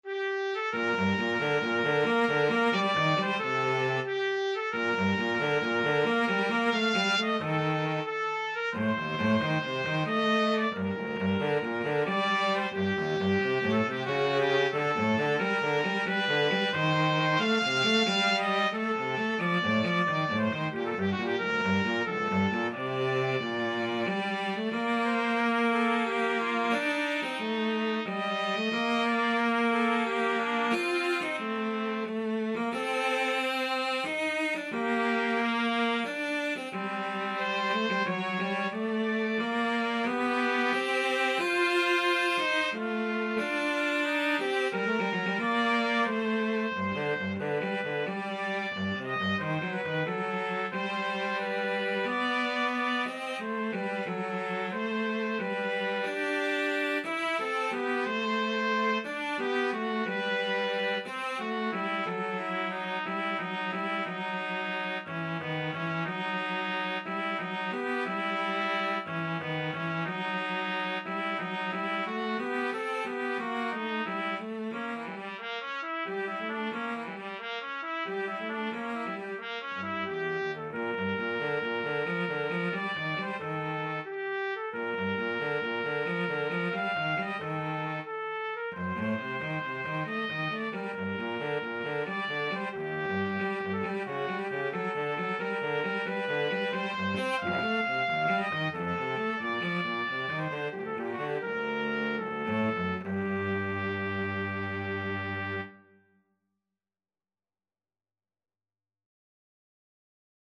3/8 (View more 3/8 Music)
Poco Allegretto = 90
Classical (View more Classical Trumpet-Cello Duet Music)